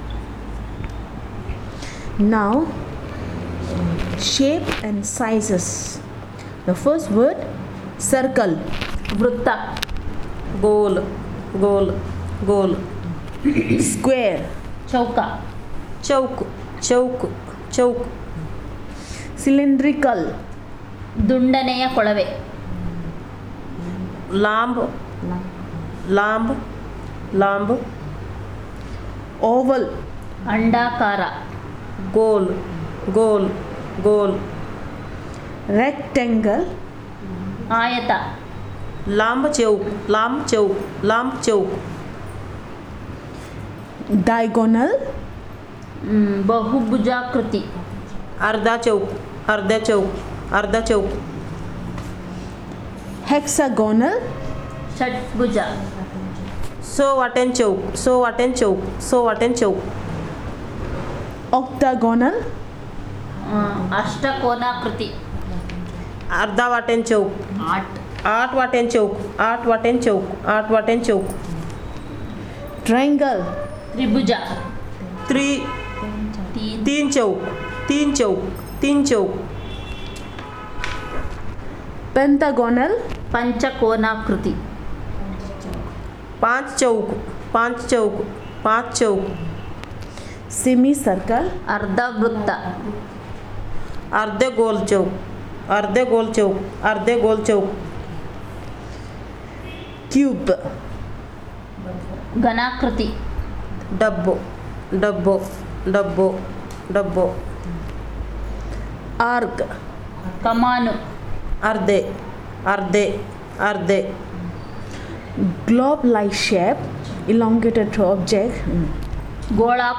Elicitation of words about shapes and sizes